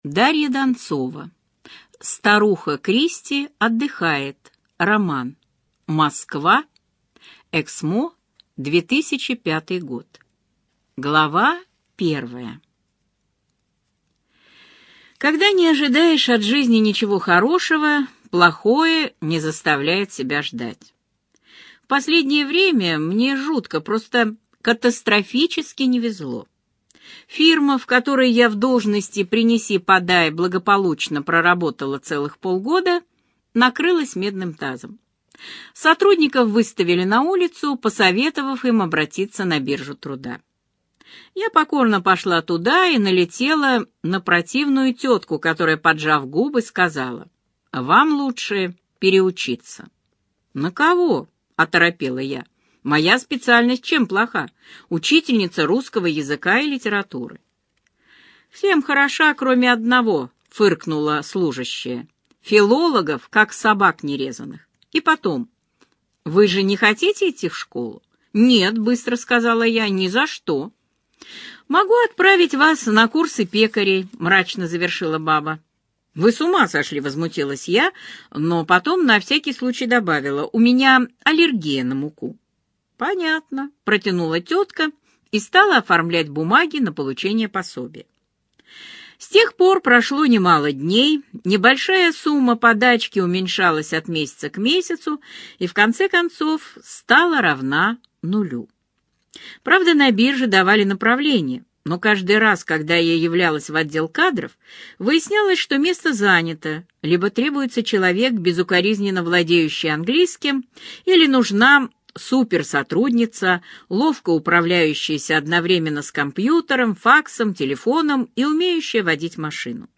Аудиокнига Старуха Кристи – отдыхает! - купить, скачать и слушать онлайн | КнигоПоиск